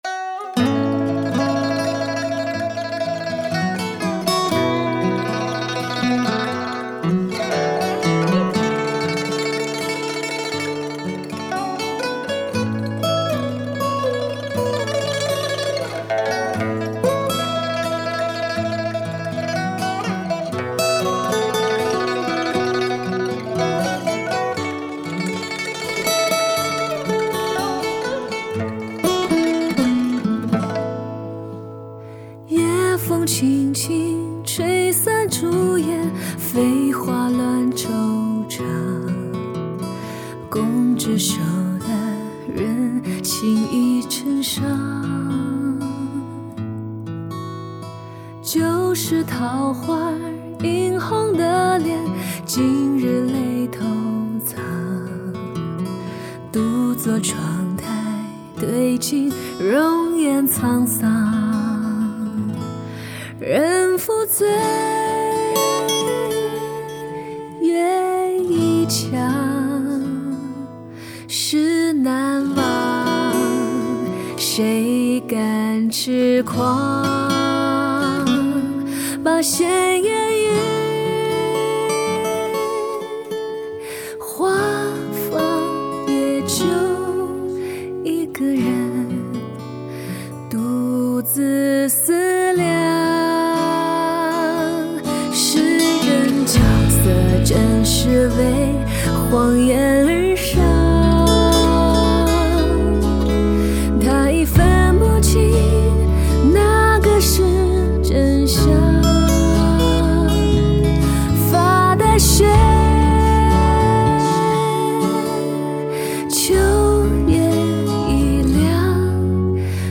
一首蕴涵深意的磅礴派情歌，为之振奋下，更加触动你我，这是一首融合了古典和现代韵味的新品情歌
歌曲巧妙运用了中国的音乐调式和音色与西洋电声乐器相结合，巧妙结合东西方乐器的精华